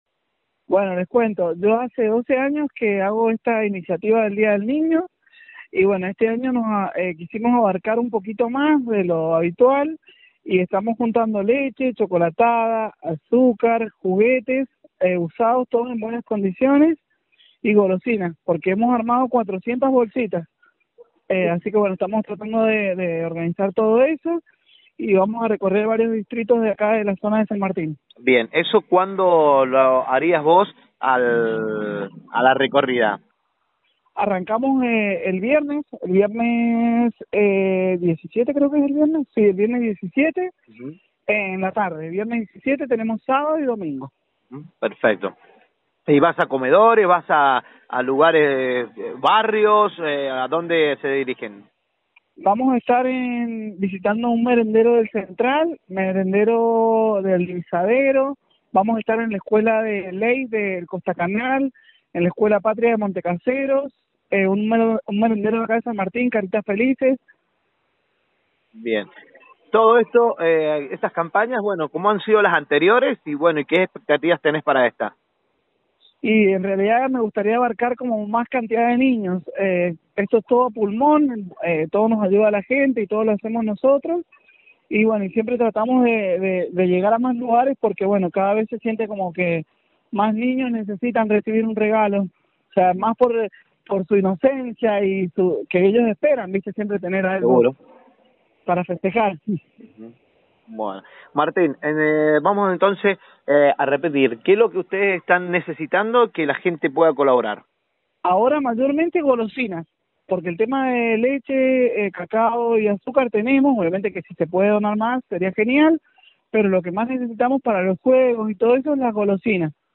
El 20 de agosto se festeja el día del niño y en Proyección 103 realizamos la entrevista de todas las mañanas, a quienes quieren ayudar a realizar distintos eventos en la zona Este.